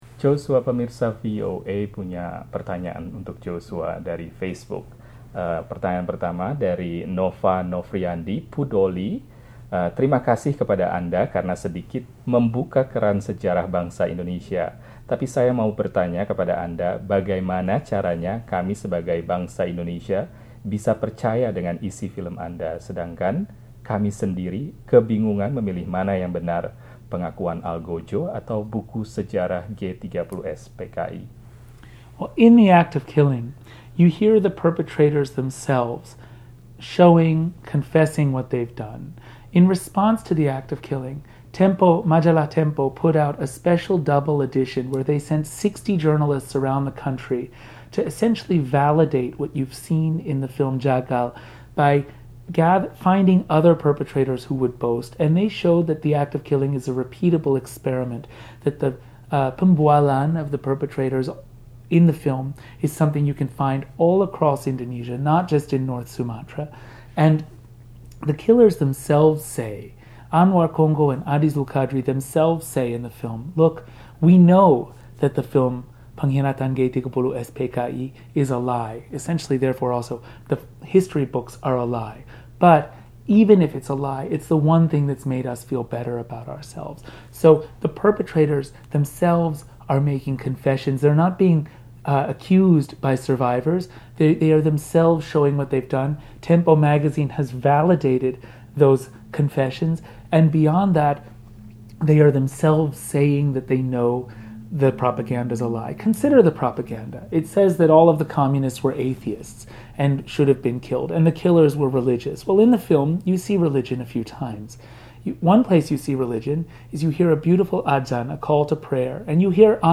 Dalam wawancara dengan VOA, Sutradara 'The Act of Killing' ('Jagal') sempat menjawab pertanyaan dari fans VOA di Facebook, termasuk mengenai rencana pembuatan film ke depannya mengenai Indonesia.